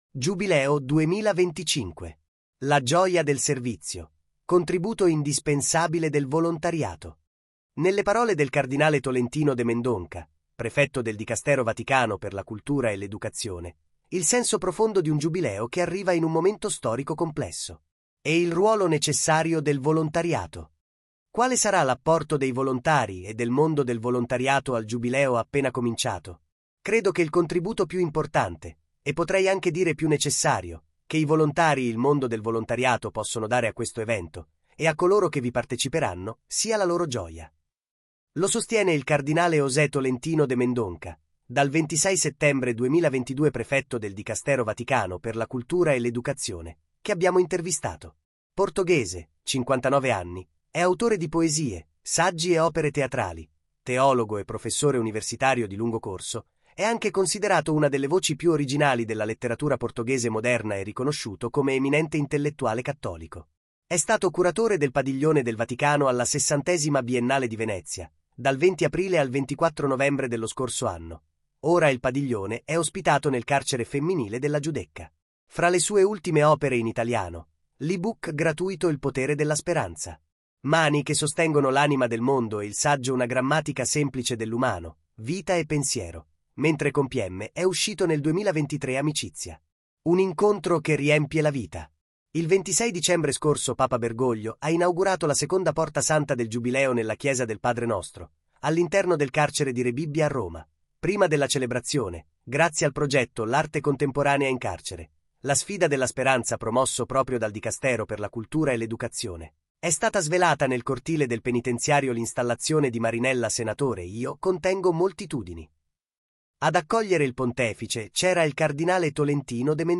intervista Mendonca Giubileo 2025